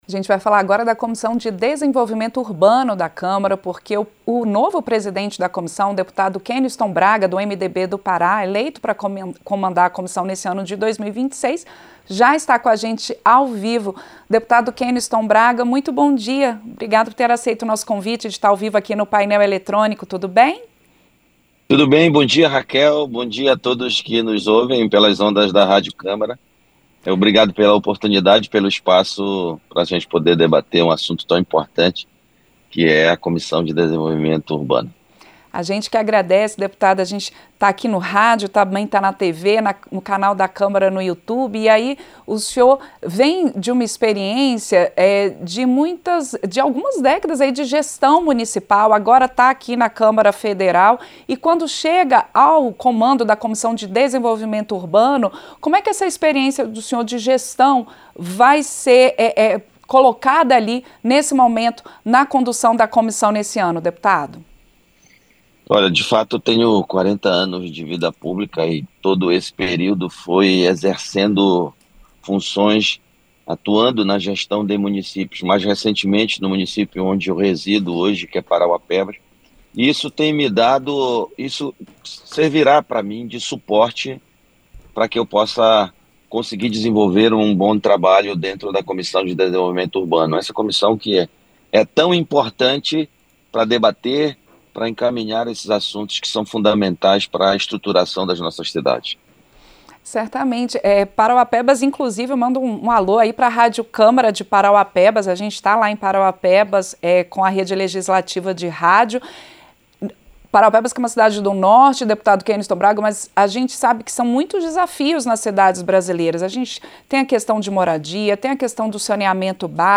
• Entrevista - Dep. Keniston Braga (MDB-PA)
Em entrevista ao Painel Eletrônico (6), o parlamentar listou problemas comuns às cidades brasileiras e que precisam de um olhar atento da comissão.
Programa ao vivo com reportagens, entrevistas sobre temas relacionados à Câmara dos Deputados, e o que vai ser destaque durante a semana.